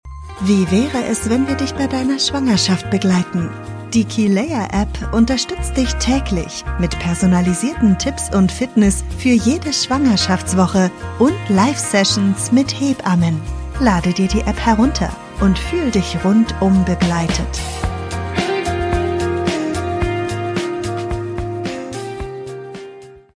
Werbung - DWL